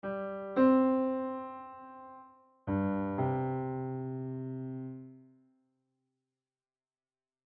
You_Are_Loved_Pitches.mp3